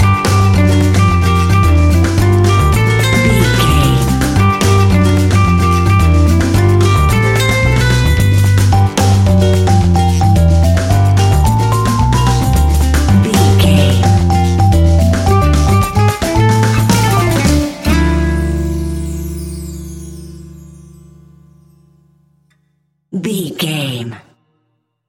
An exotic and colorful piece of Espanic and Latin music.
Aeolian/Minor
maracas
percussion spanish guitar
latin guitar